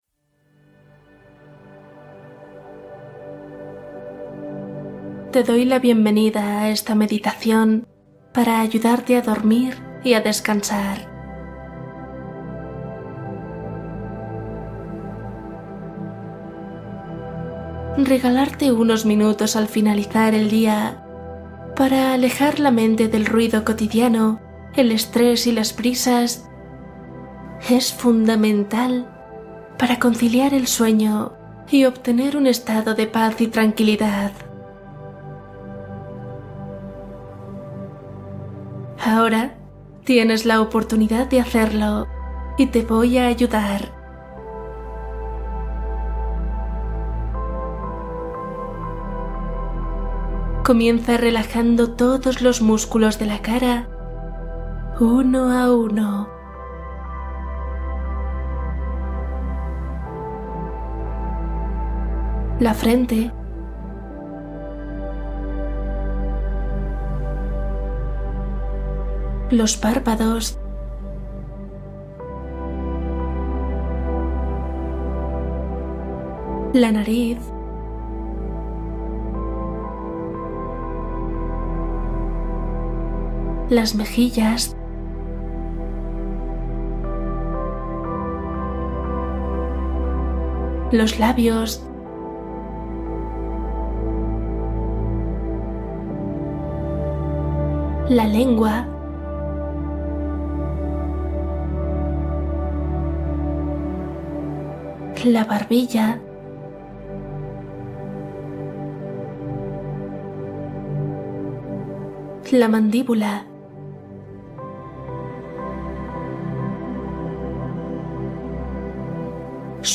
Una pausa guiada para facilitar la llegada del sueño